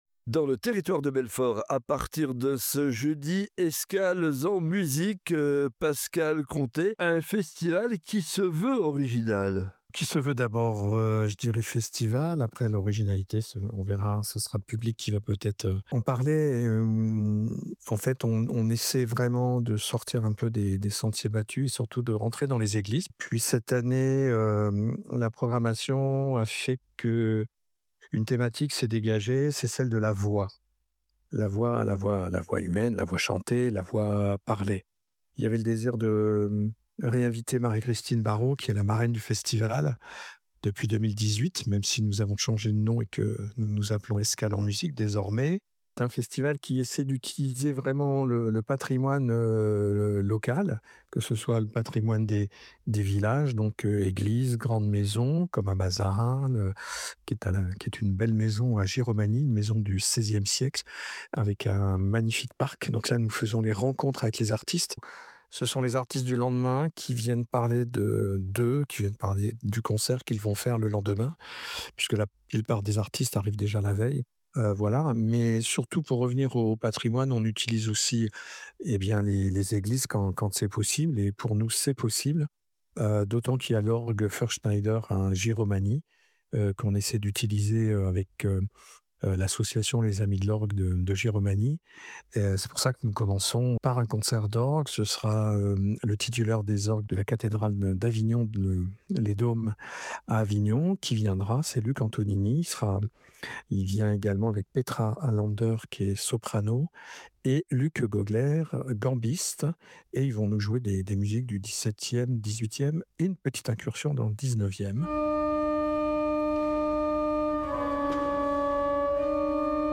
Présentation du festival